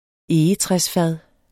Udtale [ ˈeːjətʁεs- ]